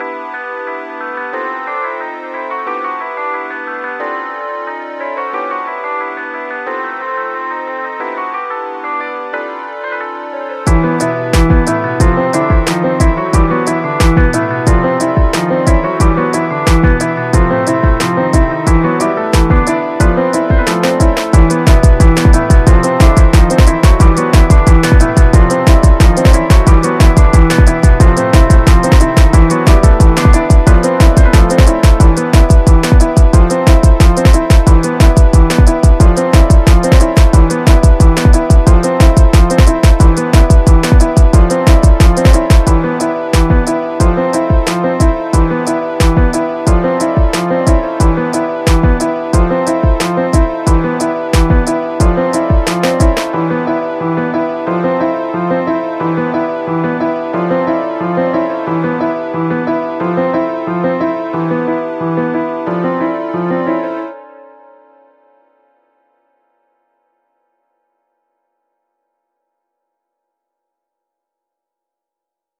this one is based on undertale so it has so leitmotifs of it. i also made this on a flight :3